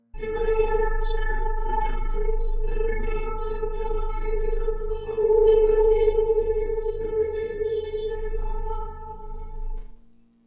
Source: "in my hands" (11:24-11:35)
Processing: Granulated, 10 voi., stretch 1:5 + KS = 67, F = 1023